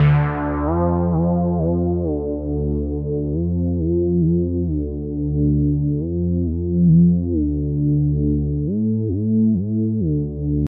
低音Wirr Warr " 低音Wirr Warr0190bpm
描述：低音吉他的声音与另一个声音在高的峰值。
标签： 巴斯 巴斯基地
声道立体声